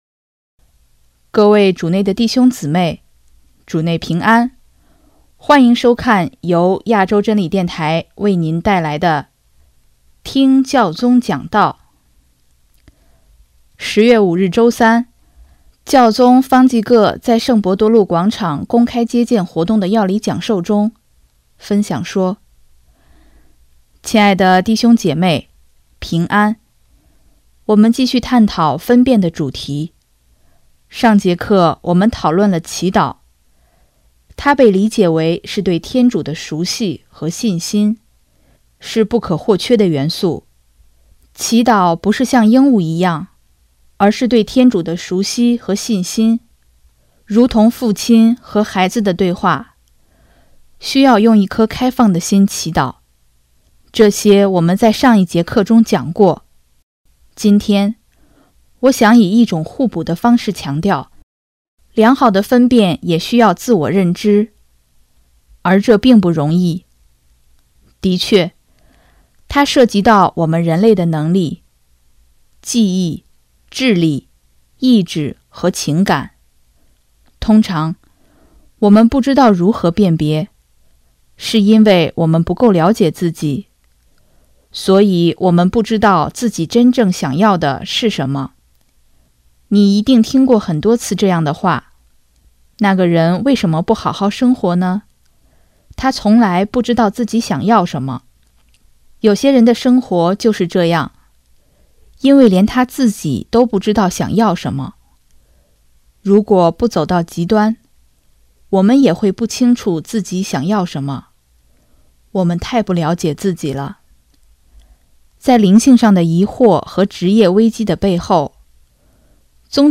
10月5日周三，教宗方济各在圣伯多禄广场公开接见活动的要理讲授中，分享说：